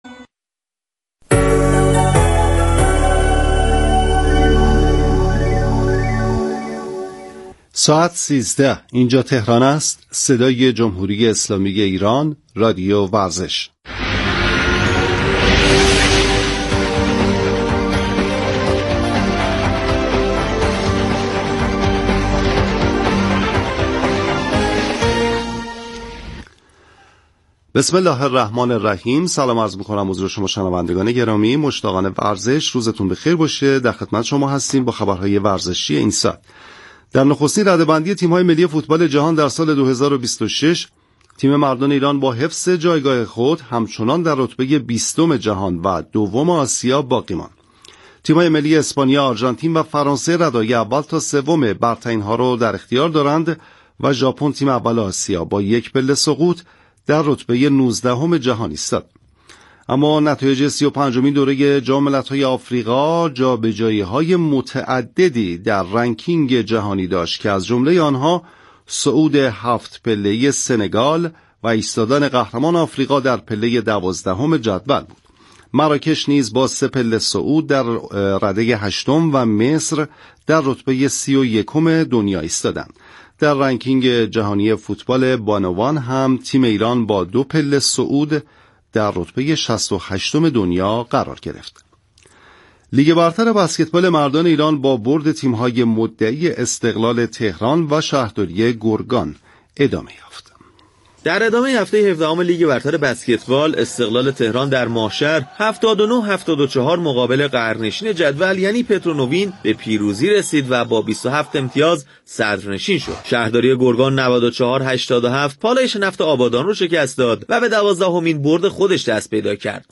/پزشکی ورزشی و رادیو ورزش/